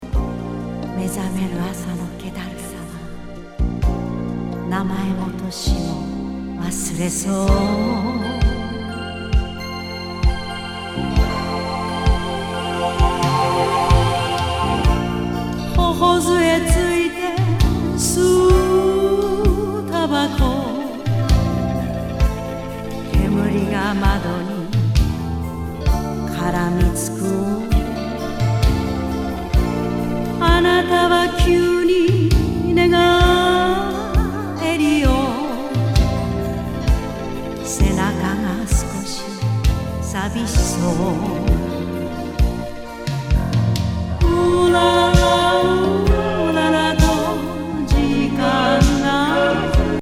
ドリーミー歌謡+喋り入り